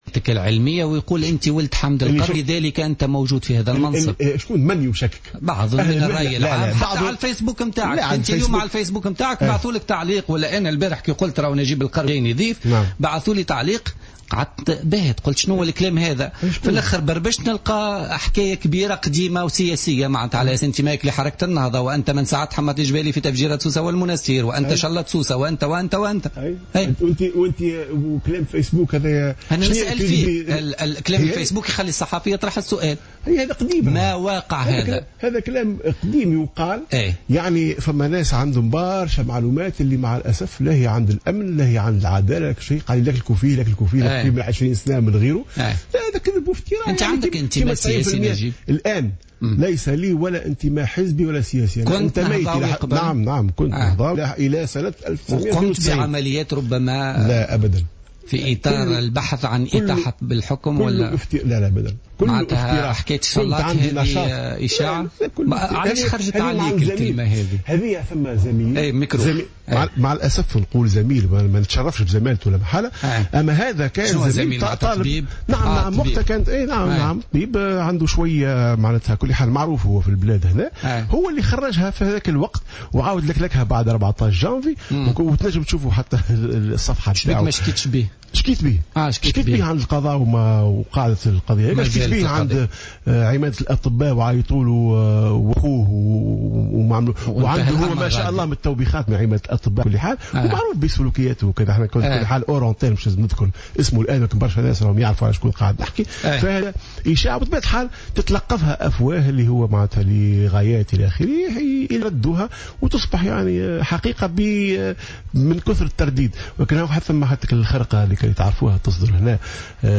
ضيف بوليتيكا اليوم